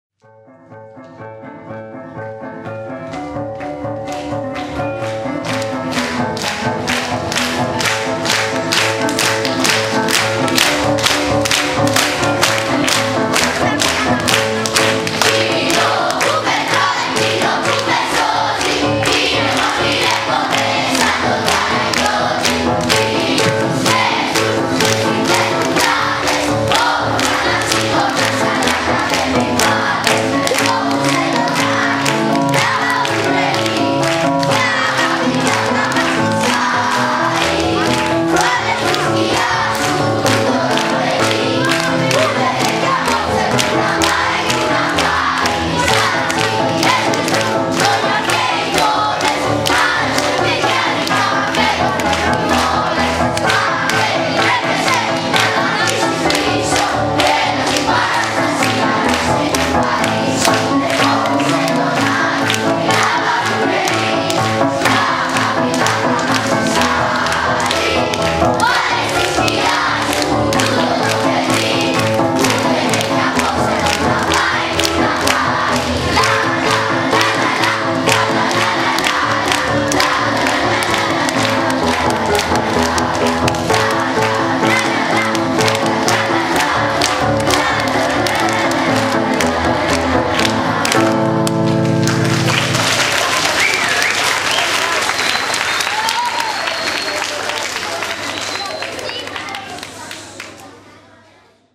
Για όλους εμάς που απολαύσαμε τους μαθητές μας στο κείμενο και τα τραγούδια της παράστασης για την επέτειο της 25ης Μαρτίου αλλά και για όλους αυτούς που δεν είχαν την ευκαιρία να τα παρακολουθήσουν, ανεβάζουμε ορισμένα από τα τραγούδια της παράστασης, όπως τα ερμήνευσε η χορωδία των μαθητών υπό την καθοδήγηση της μουσικού του σχολείου μας. [Η ανισομέρεια του επιπέδου έντασης της μουσικής και των τραγουδιών οφείλεται σε λάθος θέση της συσκευής καταγραφής του ήχου.